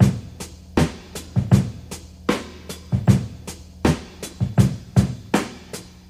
79 Bpm Tape Rock Drum Loop Sample C Key.wav
Free breakbeat sample - kick tuned to the C note.
79-bpm-tape-rock-drum-loop-sample-c-key-TiV.ogg